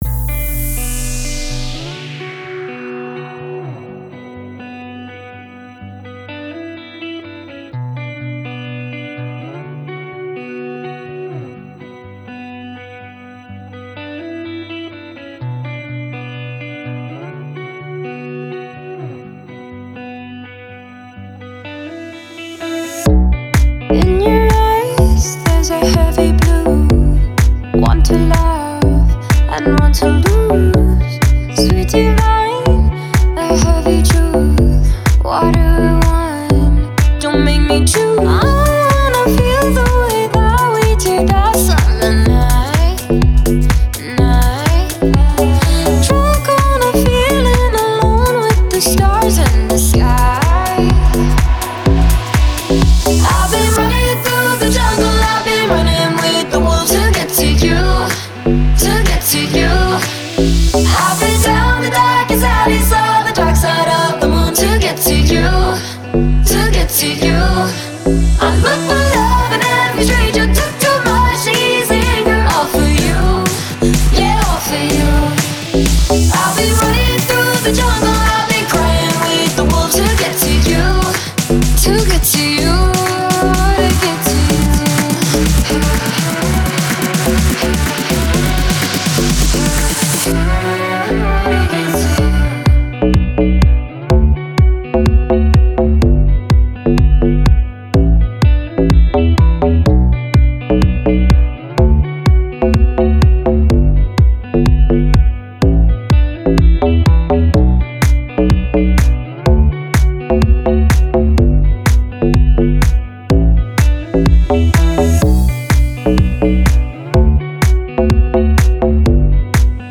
Стиль: Deep House / Club House / Vocal House